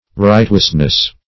Search Result for " rightwiseness" : The Collaborative International Dictionary of English v.0.48: Rightwiseness \Right"wise`ness\, n. Righteousness.